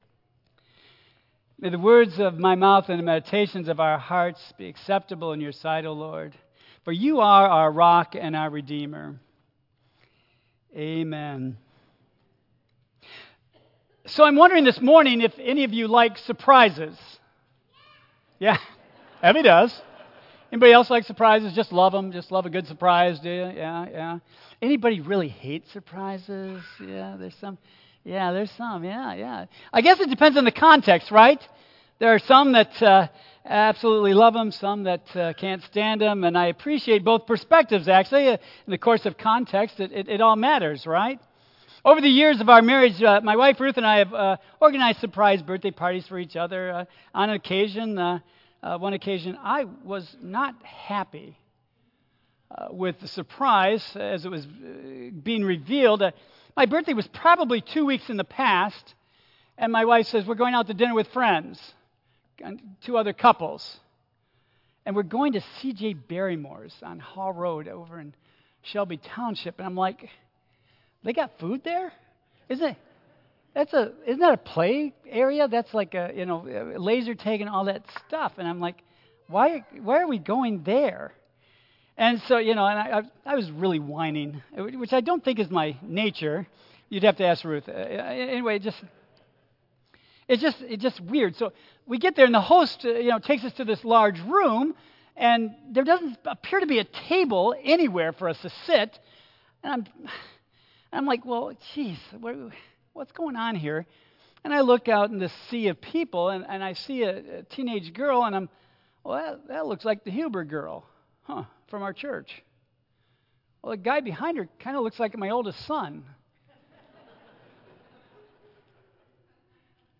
Praying with Jesus Message Series Easter Sunday Worship Hallelujiah!
Tagged with 2025 , Easter , Sermon , Waterford Central United Methodist Church , Worship